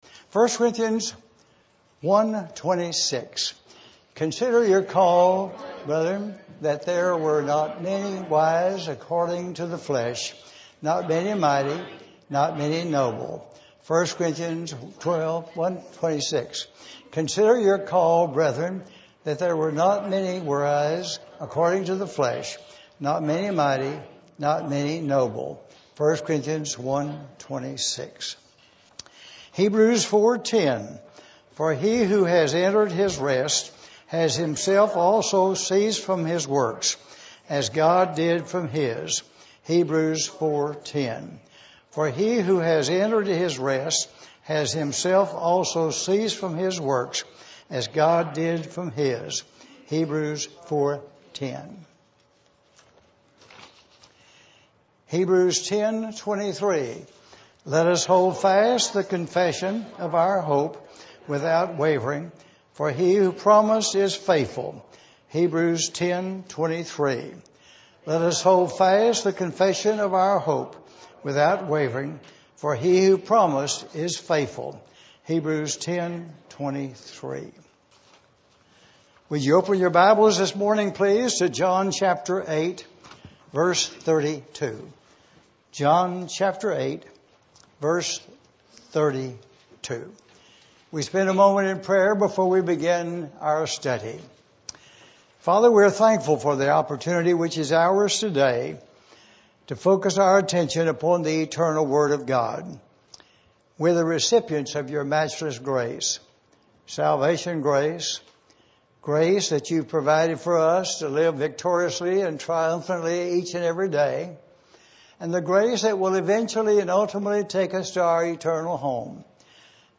The Lord's Table is observed at the end of this session study. Sermon Audio  Sermon Video (Includes overhead presentations)